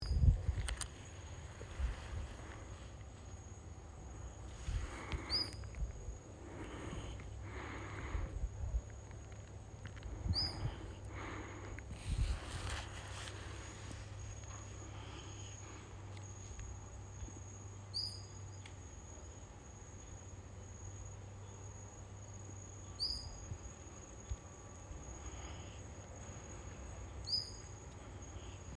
White-tailed Nightjar
Hydropsalis cayennensis
white-tailed-nightjar.mp3